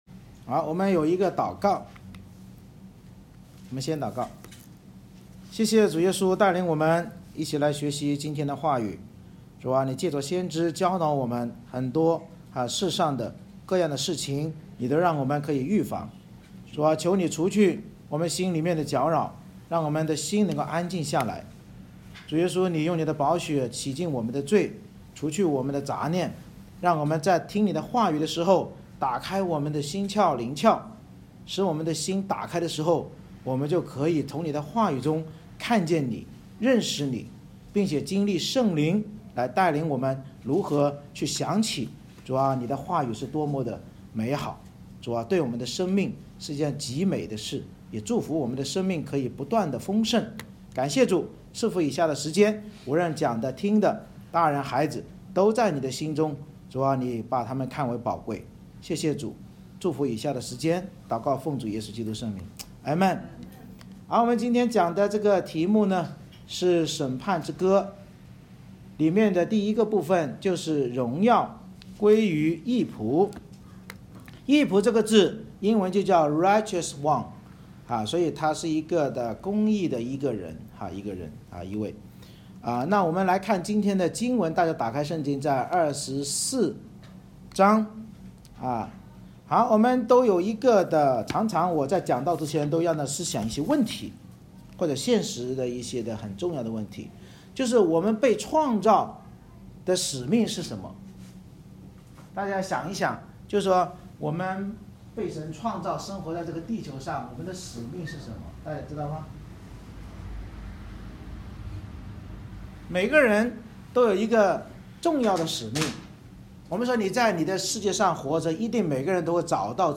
以赛亚书 Isaiah24:1-23 Service Type: 主日崇拜 先知领受神对全世界审判之后所听见的余民之歌，激励我们唯有敬畏和悔改信靠义者基督耶稣，才能在大审判中得胜并进入天上高唱荣耀归于义仆即羔羊之歌。